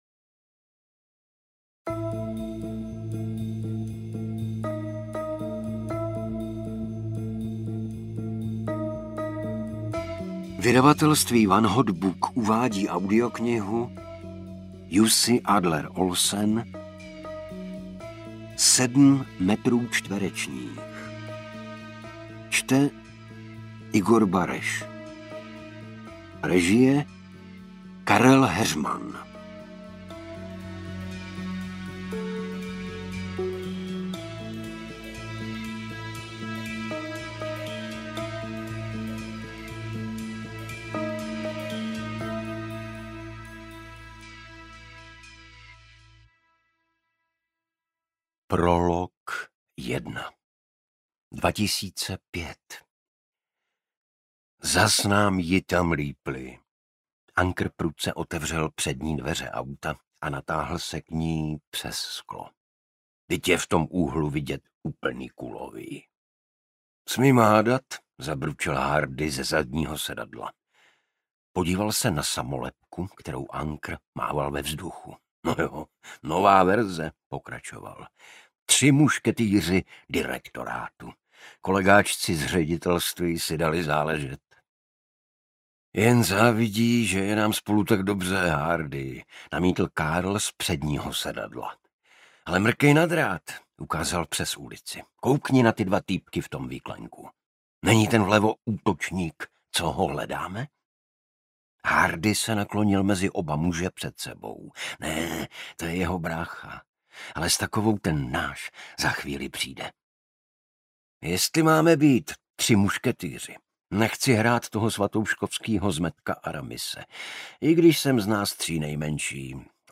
Interpret:  Igor Bareš
AudioKniha ke stažení, 80 x mp3, délka 19 hod. 52 min., velikost 1095,6 MB, česky